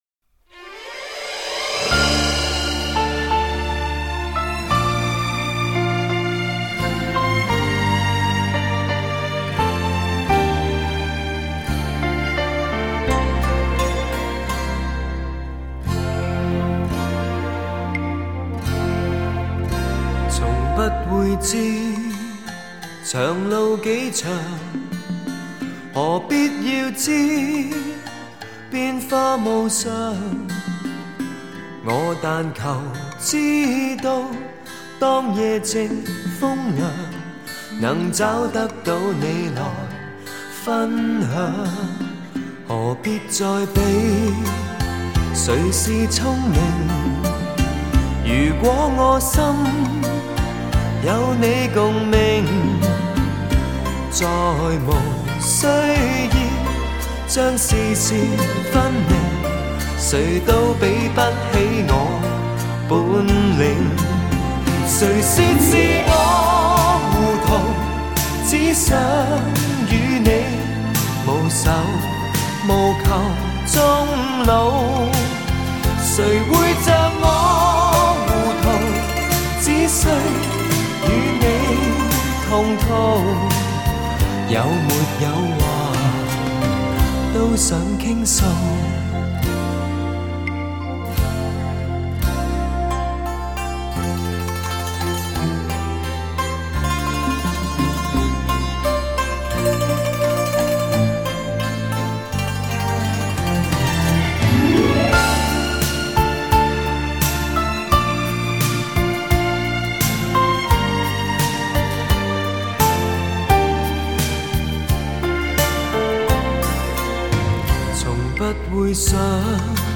这首歌是一首流行风格的歌曲。
伴着这典雅、悠长的古筝弹奏